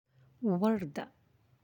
(warda)